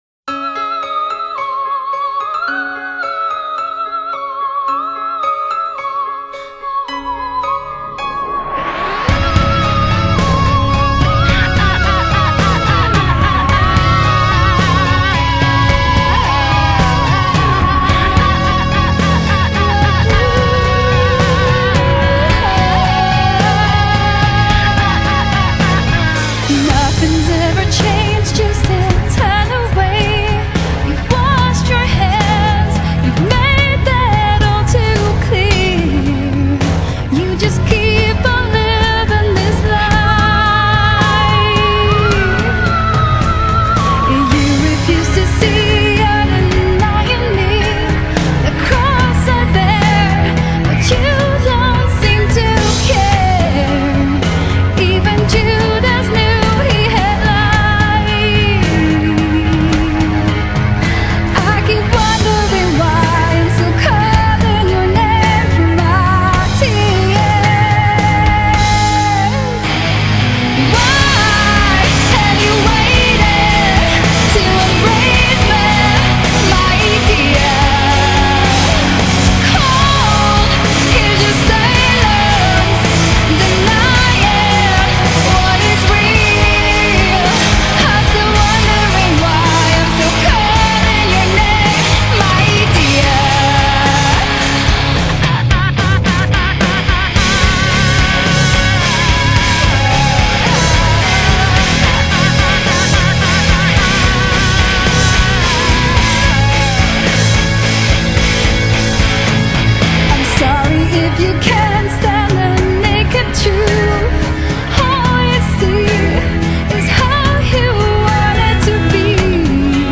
Symphonic-Gothic Rock